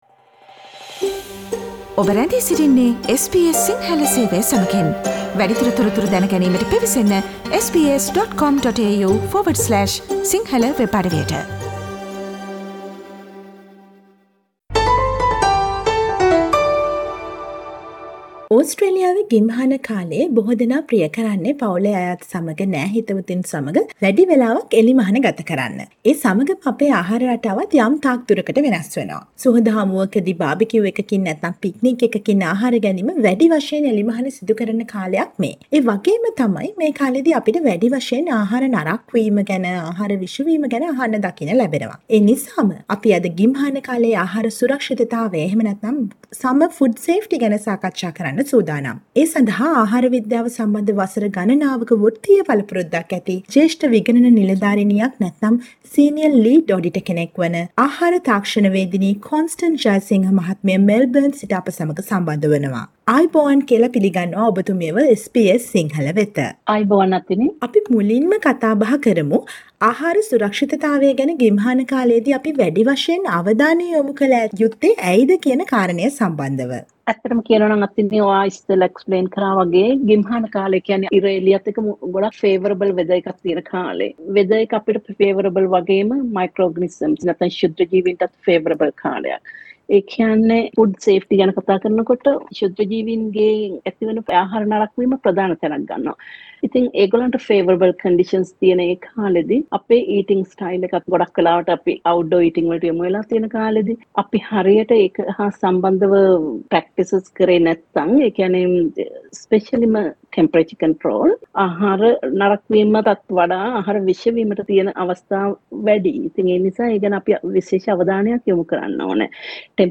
Listen to the SBS sinhala radio interview